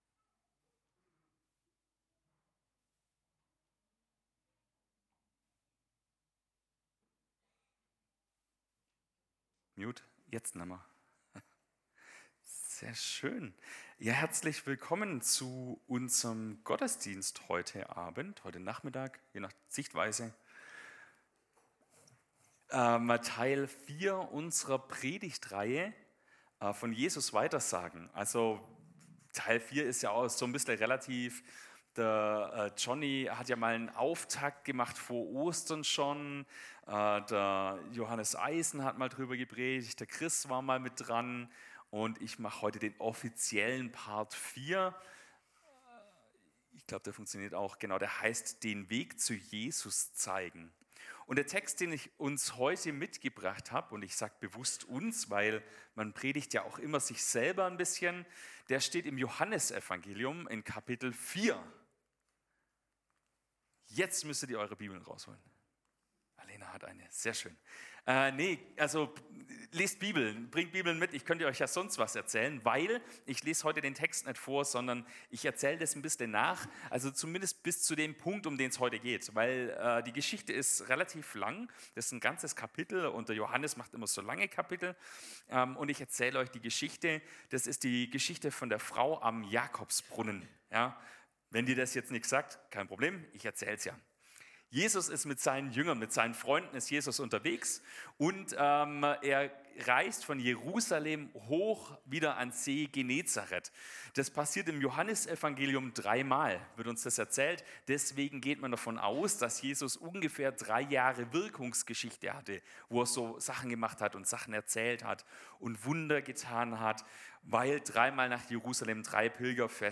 Predigt am 06.07.2025